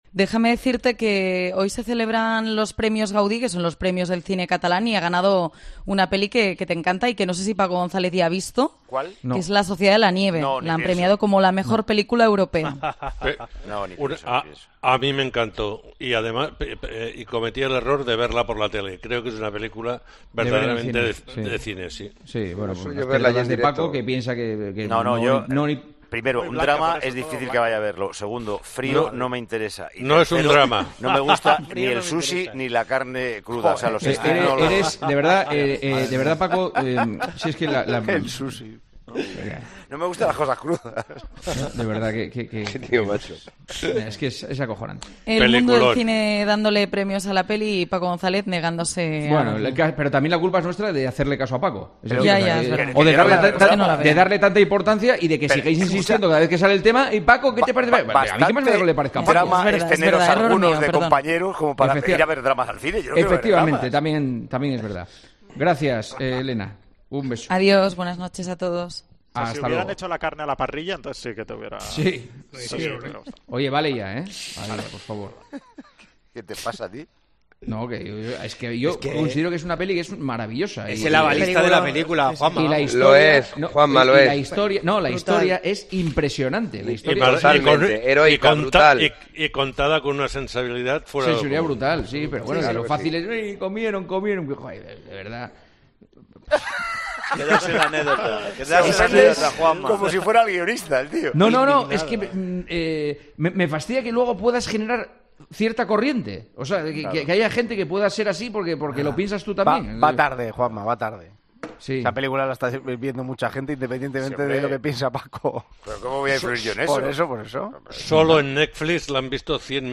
El 'debate' entre Juanma Castaño y Paco González a raíz de 'La sociedad de la nieve': "Y las moscas"
Anoche, durante el Tertulión de los domingos, tuvimos un momento más relajado para hablar de la película de Bayona después de todo el análisis sobre el derbi y la jornada liguera.